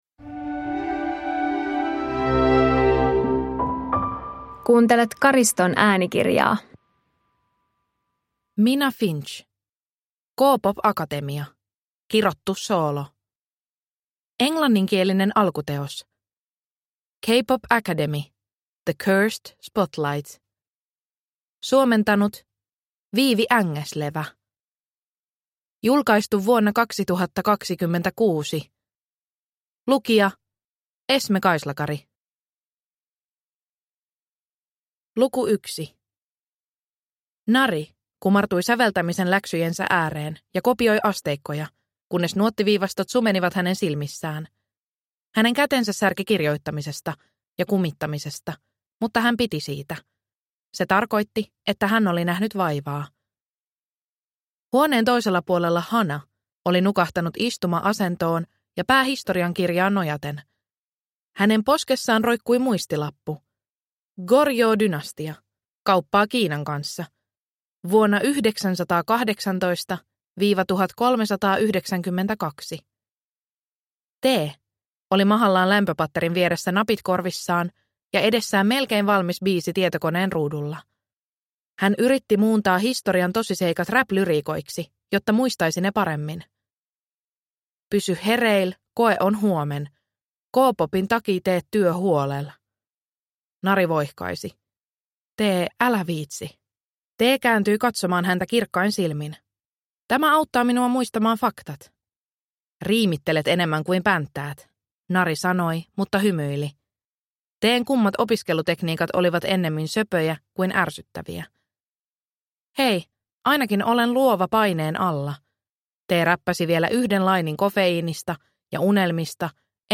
K-pop-akatemia – Kirottu soolo – Ljudbok